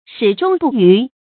注音：ㄕㄧˇ ㄓㄨㄙ ㄅㄨˋ ㄧㄩˊ
始終不渝的讀法